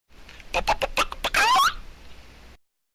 chickennoise
Category: Comedians   Right: Personal